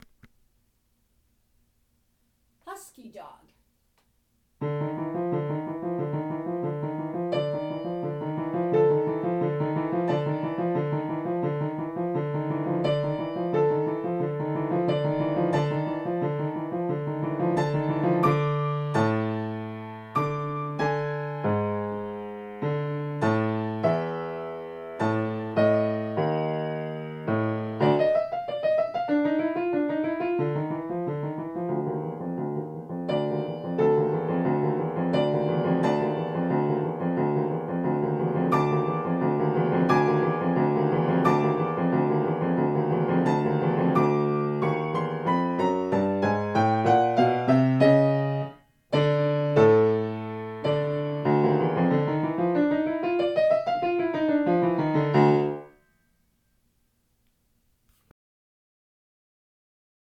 Elementary Piano